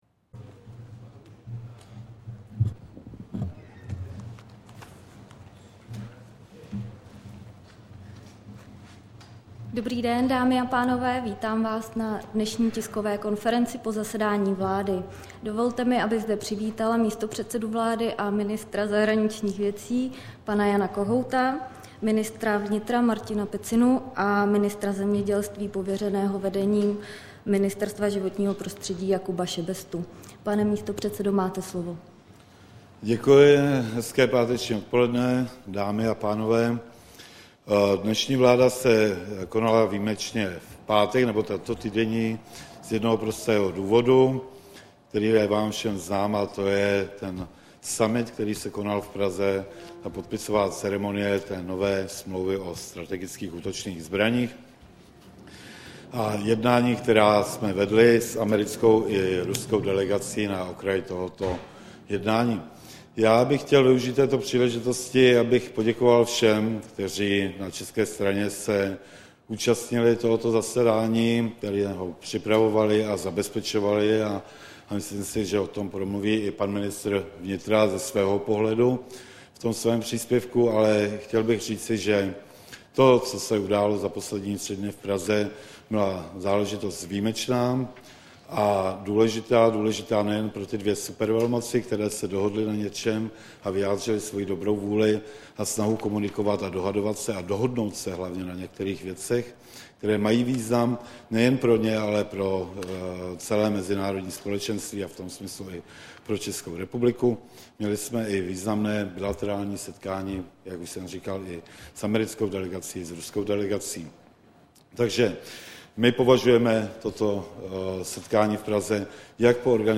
Tisková konference po jednání vlády, 9. dubna 2010